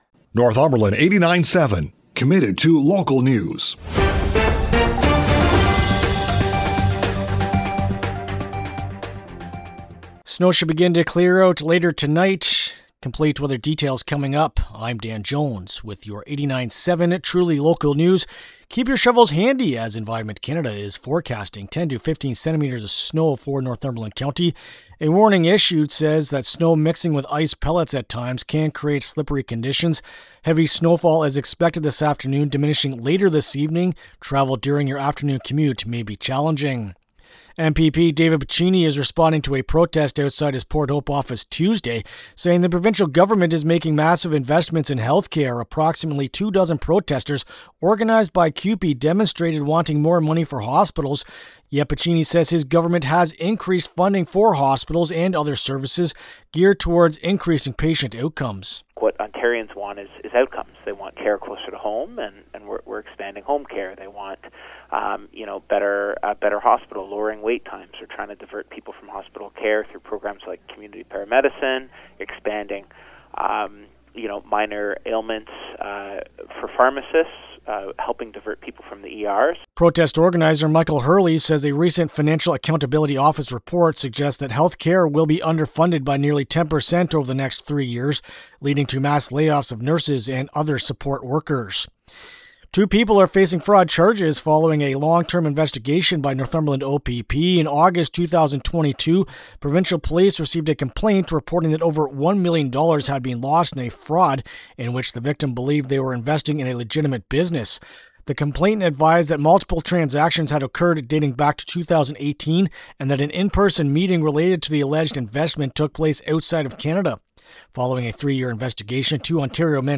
Wed.-Feb-18-PM-News-2.mp3